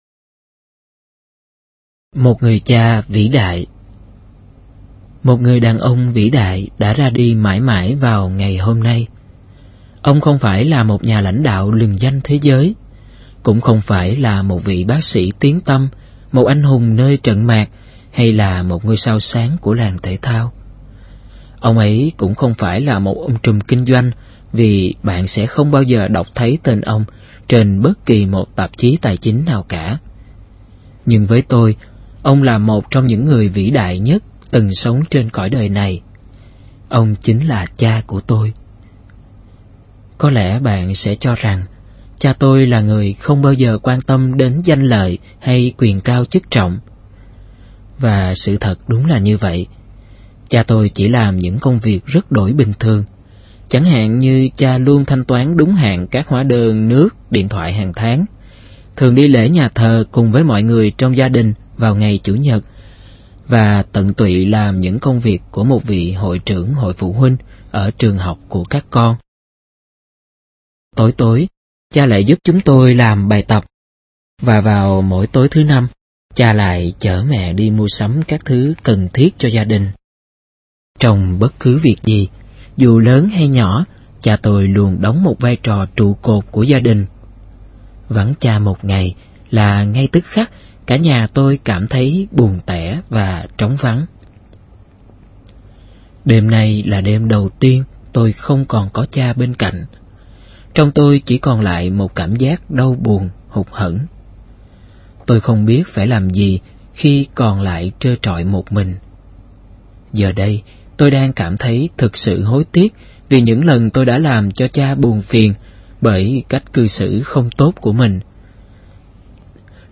Sách nói Chicken Soup 4 - Chia Sẻ Tâm Hồn Và Quà Tặng Cuộc Sống - Jack Canfield - Sách Nói Online Hay